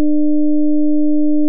audacityの正弦波ジェネレータで生成しやすいように、フーリエ級数展開しました。
0.220+0.255*cos2π×300t+0.487cos2π×600t+0.0332*cos2π×900t